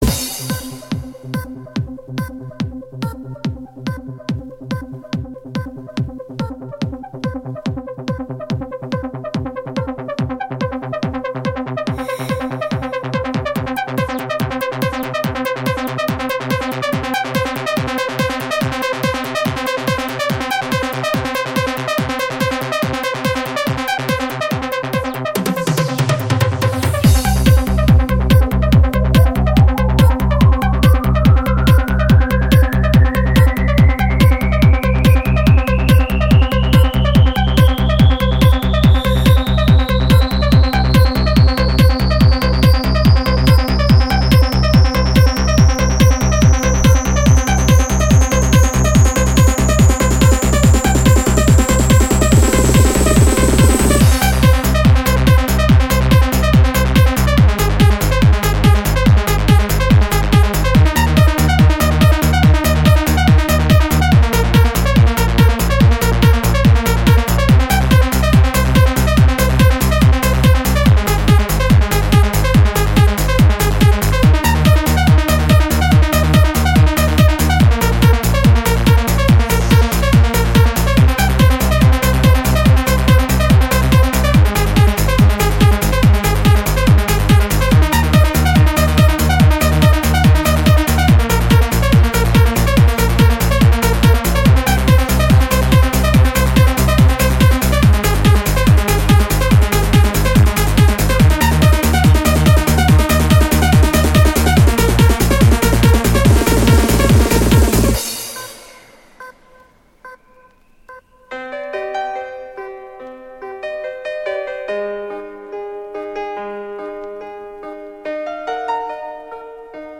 Жанр: Electro